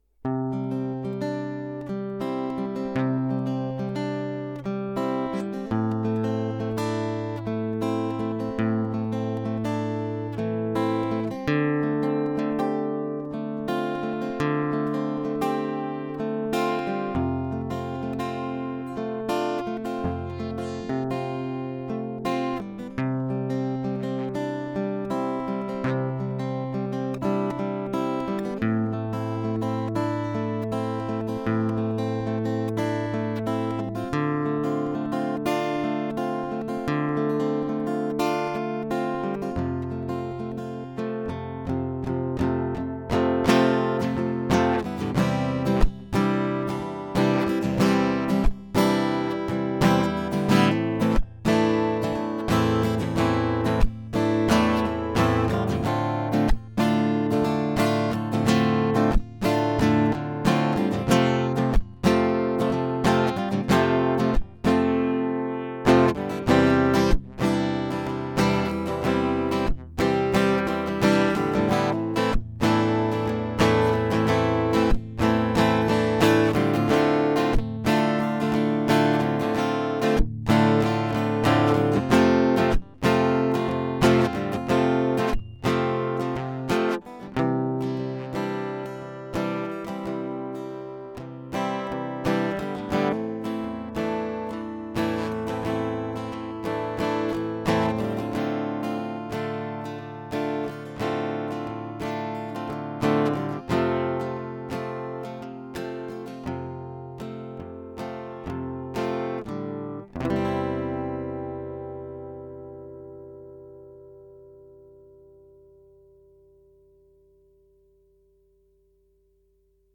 [Workshop] Einbau eines Sennheiser ME865 Mikrofonmoduls mit "DiY-Entkopplungs-Spinne" in meine Lowden S22 (O22)
Deshalb hatte ich eine Weile überlegt was ich denn am Einbau ändern könnte um weniger Griff-, Klopf- und sonstige Korpusgeräusche und mehr puren Akustik-Sound mit dem guten ME 865 einfangen zu können.